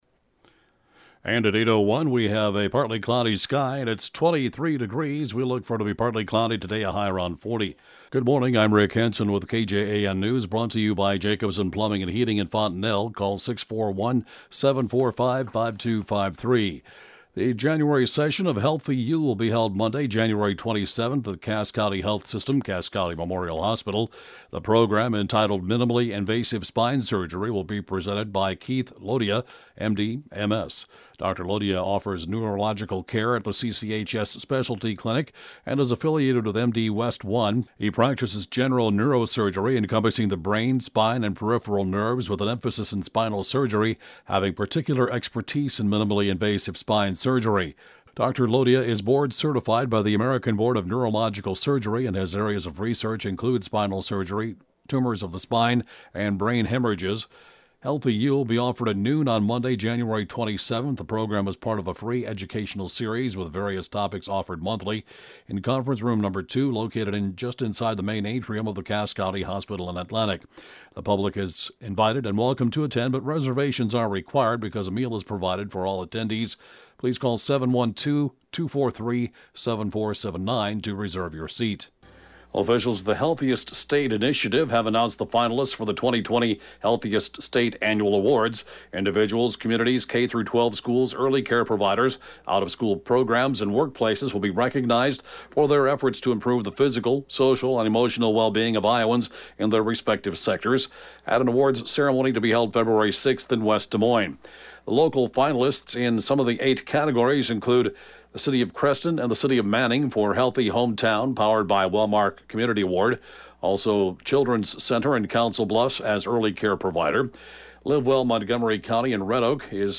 (Podcast) KJAN 8-a.m. News, Jan. 7, 2020